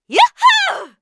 cheers2.wav